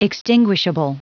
Prononciation du mot extinguishable en anglais (fichier audio)
Prononciation du mot : extinguishable